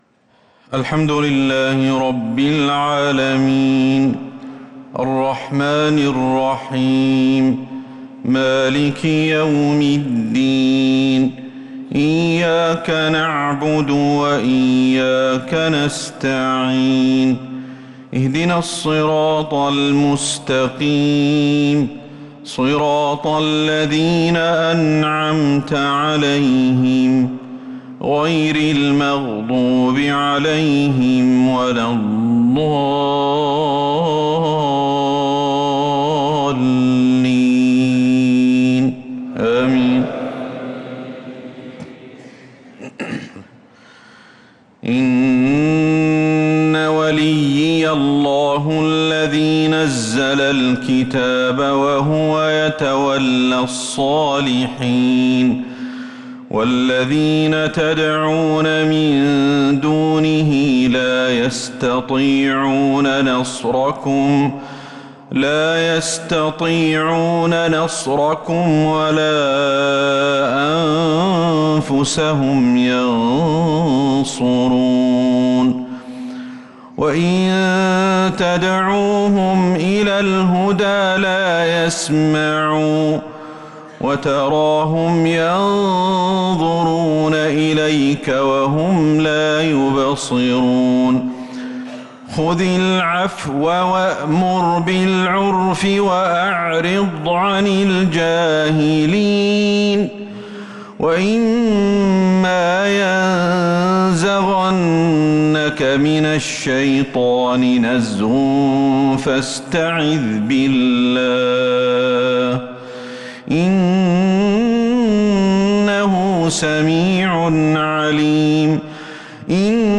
مغرب الجمعة 3-7-1446هـ من سورة الأعراف 196-205 | Maghrib prayer from Surat Al-Araf 3-1-2025 > 1446 🕌 > الفروض - تلاوات الحرمين